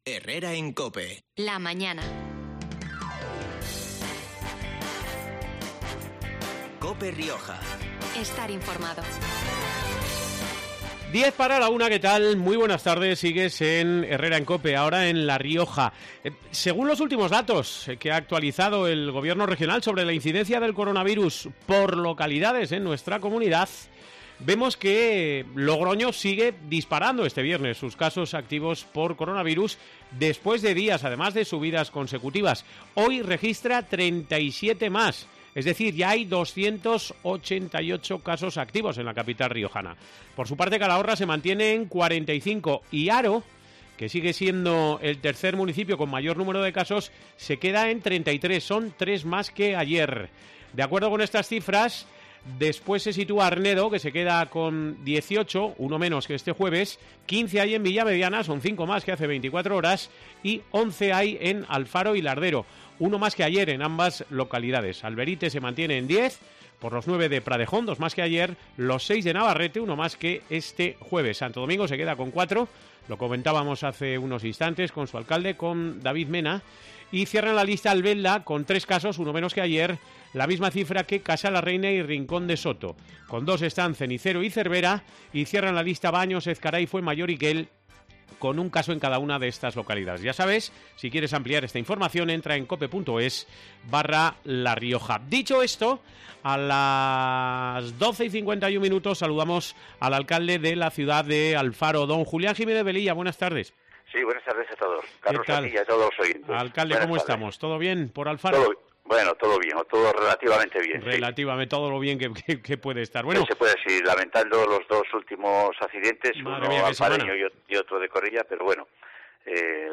Julián Jiménez Velilla es el alcalde alfareño y este viernes comentaba el capítulo taurino de su ciudad en los micrófonos de COPE Rioj